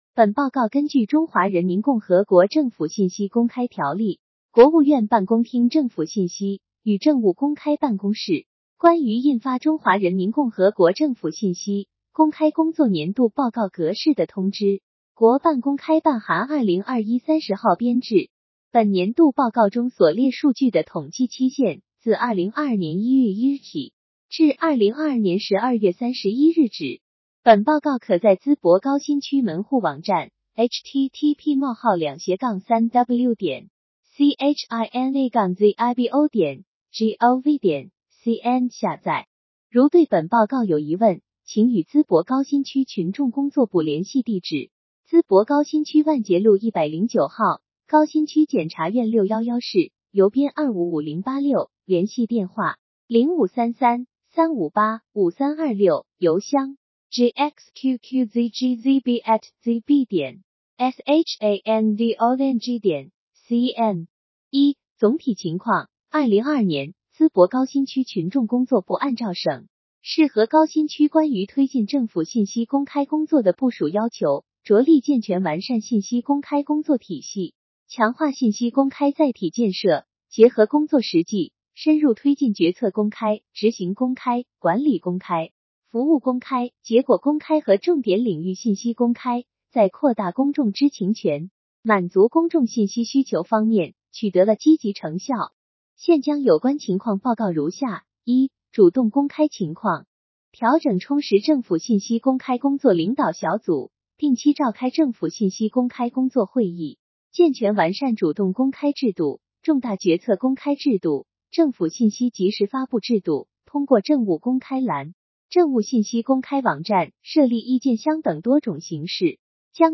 有声朗读   |    图文解读